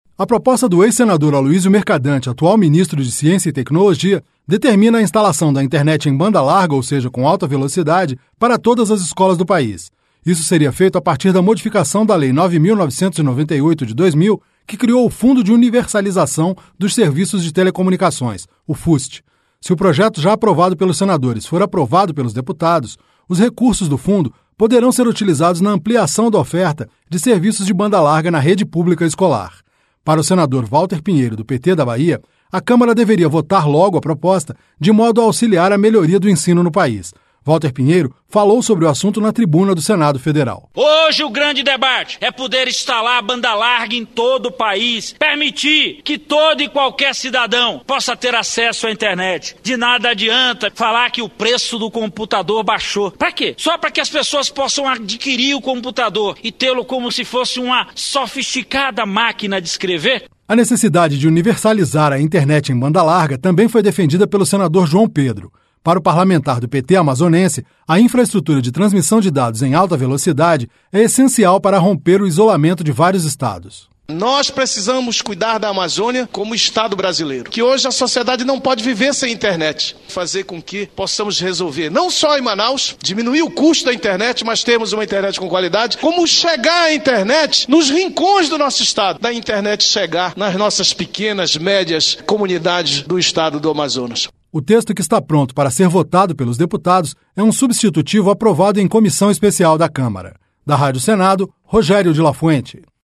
Walter Pinheiro falou sobre o assunto na tribuna do Senado Federal.